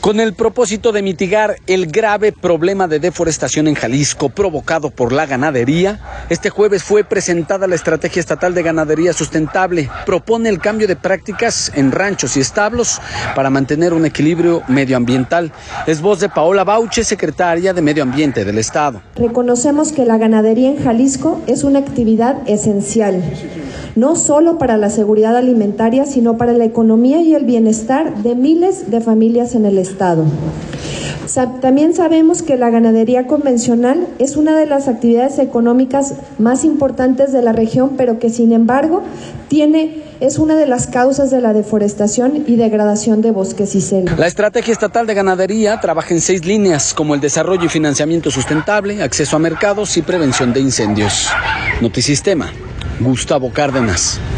audio Con el propósito de mitigar el grave problema de deforestación en Jalisco, provocado por la ganadería, este lunes fue presentada la estrategia estatal de ganadería sustentable. Propone el cambio de prácticas en ranchos y establos para mantener un equilibrio medioambiental. Es voz de Paola Bauche, secretaria de Medio Ambiente del estado.